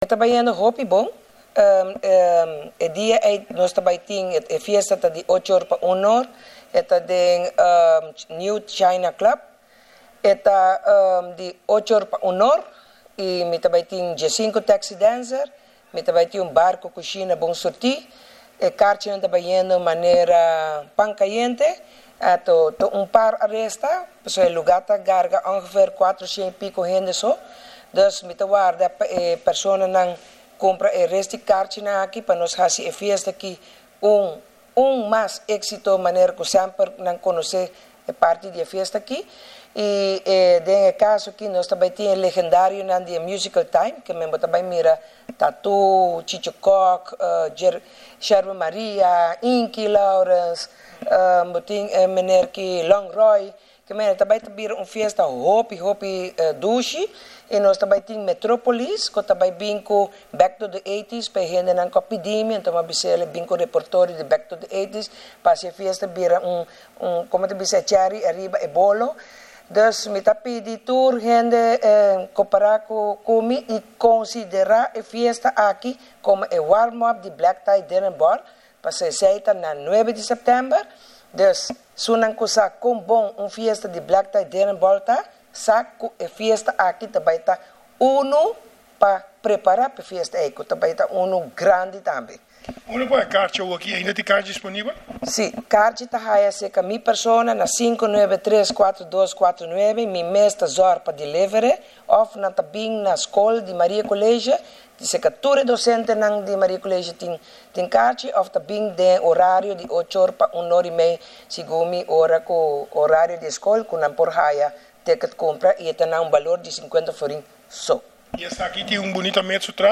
INTERVIEWS – Maria College cu su Projecto Recreativo, algun docente lo elabora ariba e parti aki